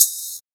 • Shaker Sound Sample G# Key 29.wav
Royality free shaker tuned to the G# note. Loudest frequency: 8592Hz
shaker-sound-sample-g-sharp-key-29-urp.wav